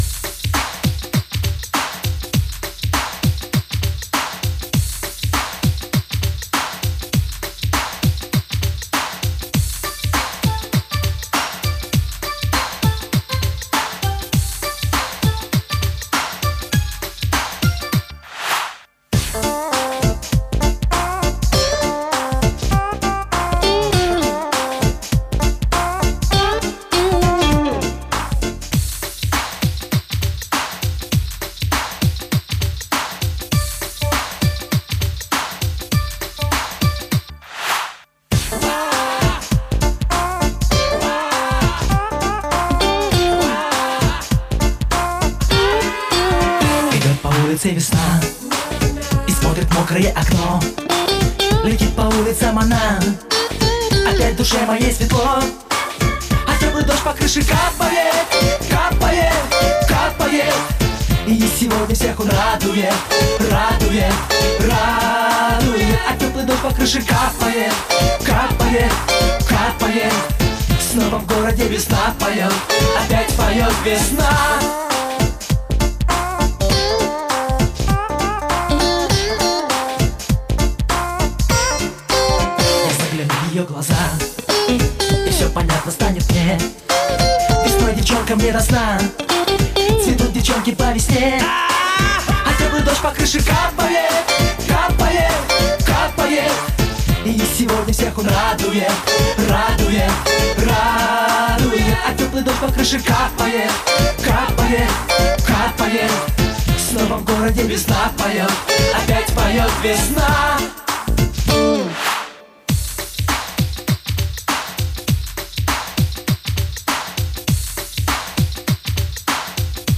Жанр: Попса